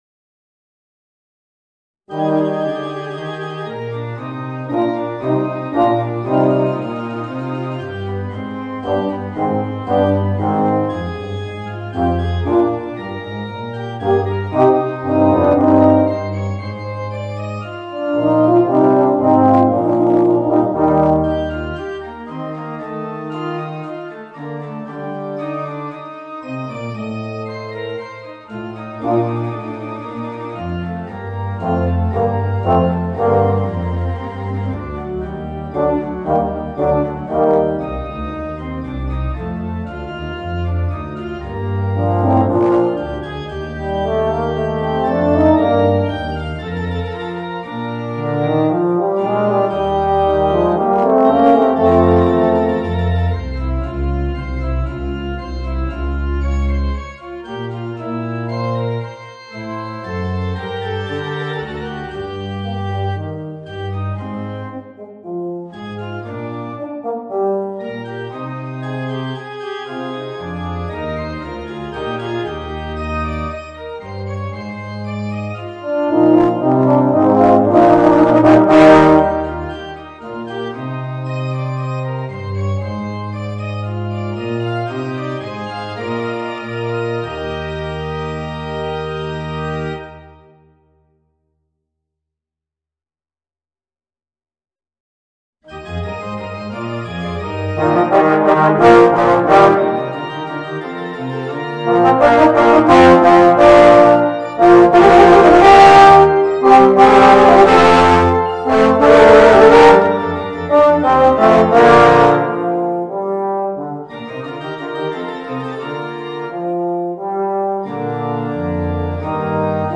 Voicing: 3 Euphoniums and Piano